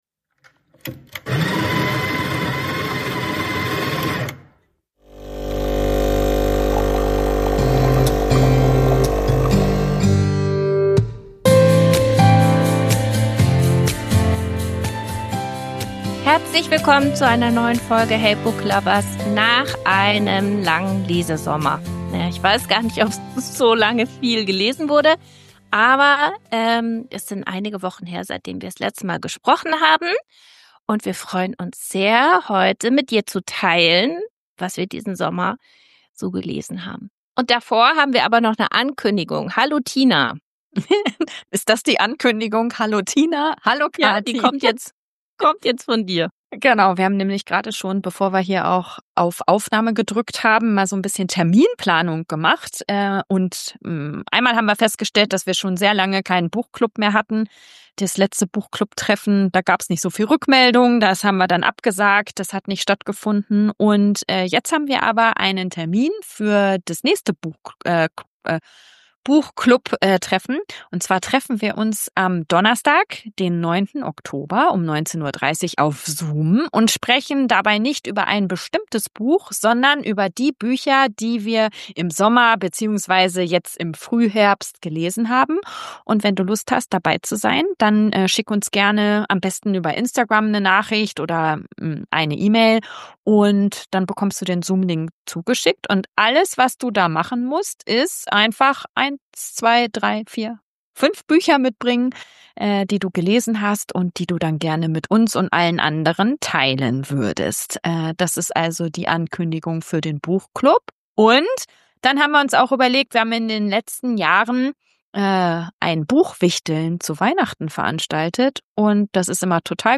Am Ende wird noch viel gelacht und auch so einiges über unsere Lesegewohnheiten verraten.